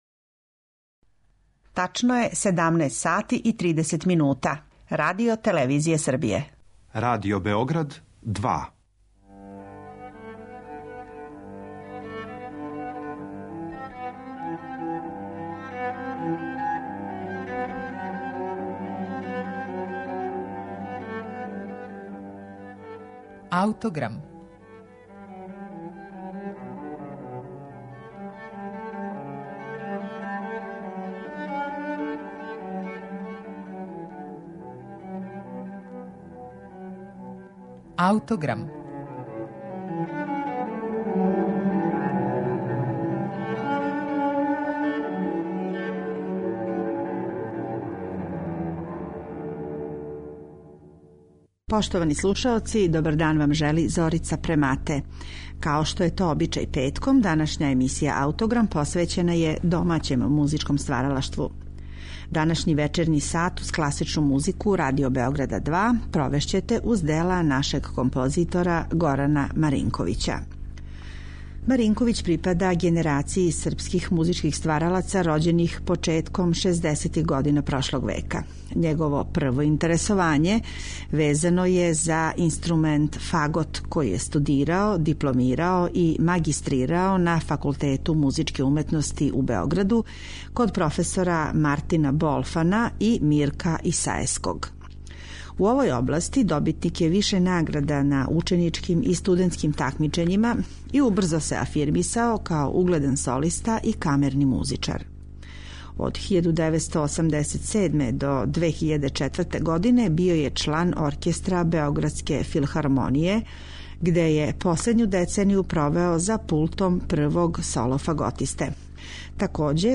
Његов постмодерни музички говор биће сагледан кроз композицију „1000 година бело", коју свира Оркестар Београдске филхармоније, а диригује Бојан Суђић.